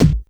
Kick_65.wav